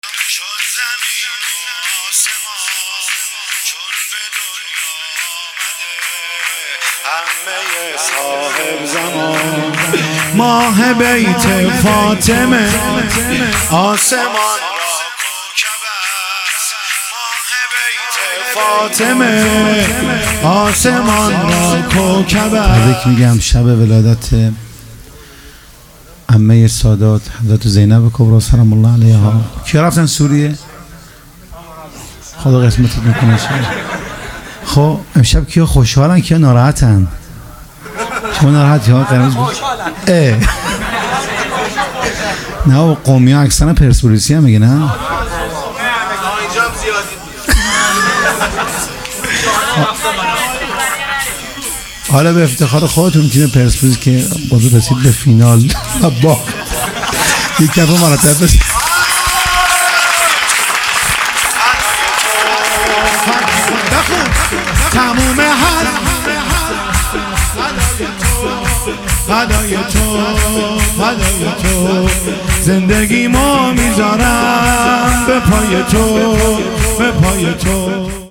میلاد حضرت زینب (س)